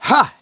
Siegfried-Vox_Attack1.wav